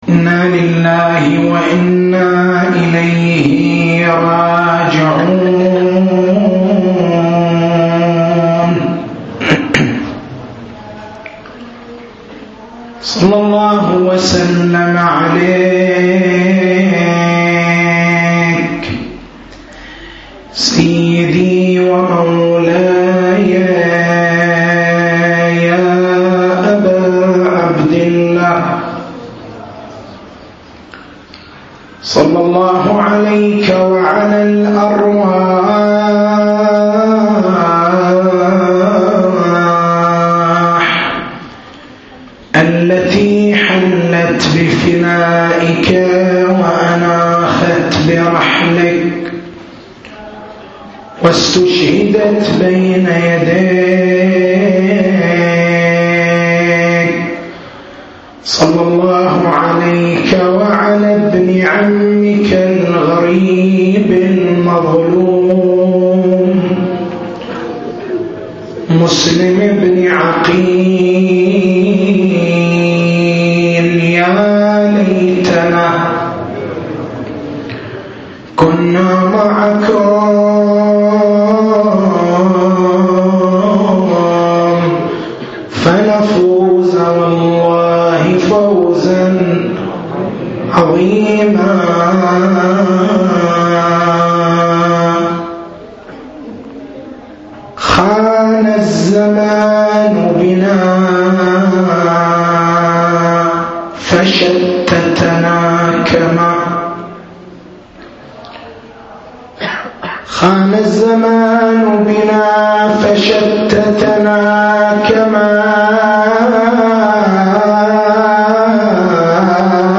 محاضرة
في الليلة الرابعة من موسم محرم الحرام عام 1430هـ. ما الفرق بين عنوان (الانتماء إلى الدين) وعنوان (تمثيل الدين)؟